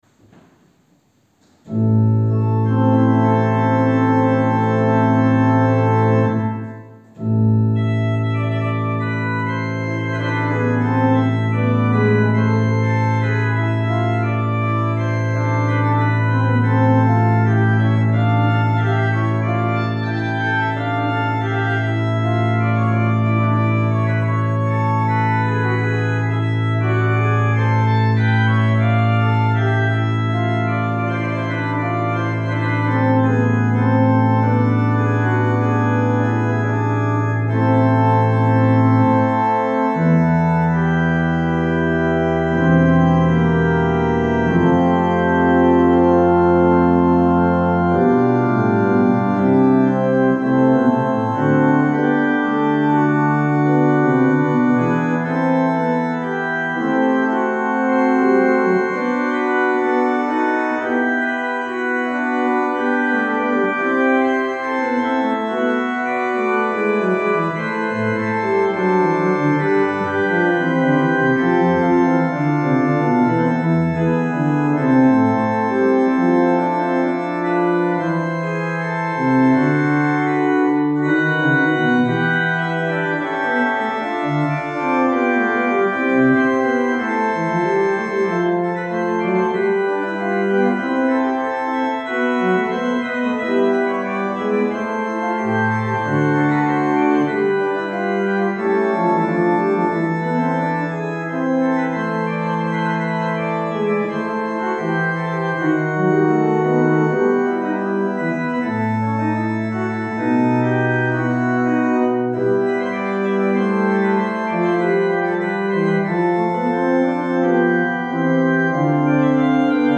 Gottesdienst aus der reformierten Erlöserkirche,
Orgelvorspiel